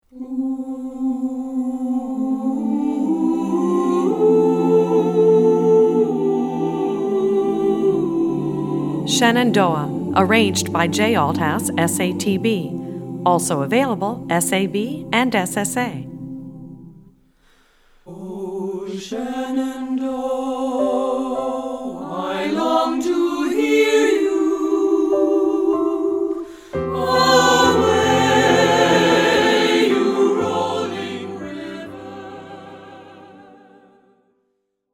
Voicing: Tenor Saxophone